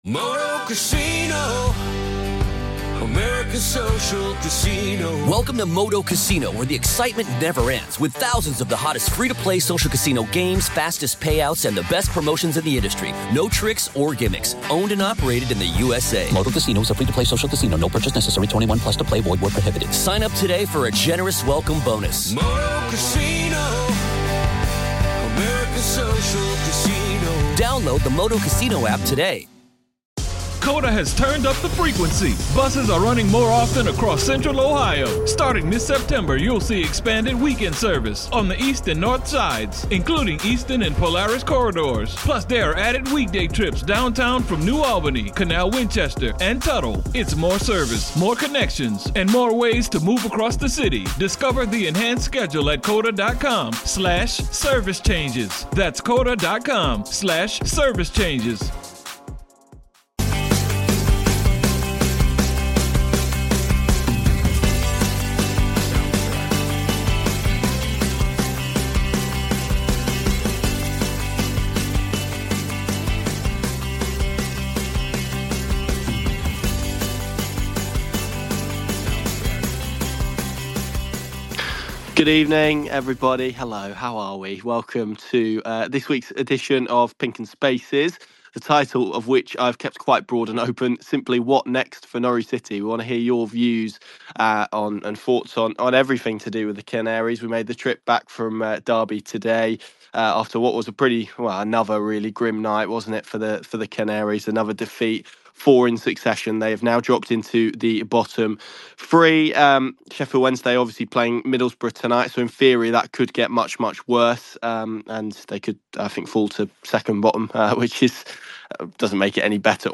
#3 Where do Norwich City go from here? | Pink Un X Spaces